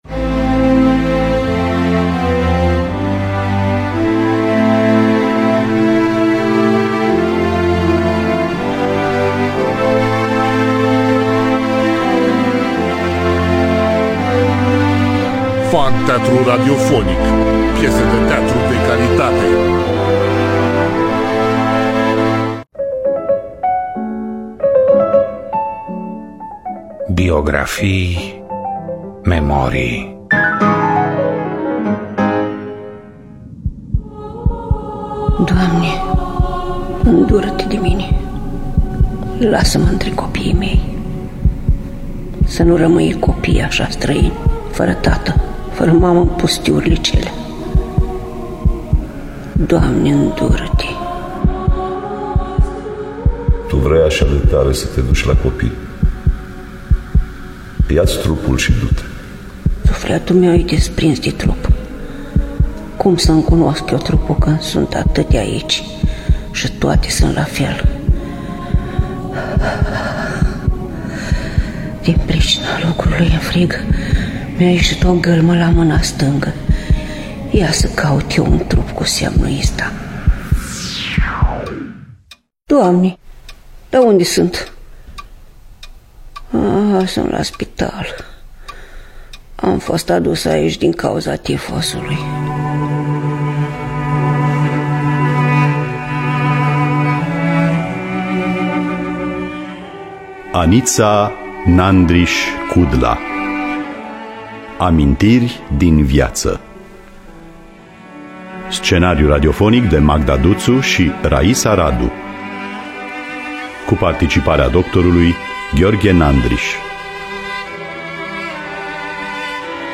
Scenariu radiofonic de Magda Duţu şi Raisa Radu, dupã volumul "20 de ani în Siberia" de Aniţa Nandriş-Cudla, publicat la Editura Humanitas.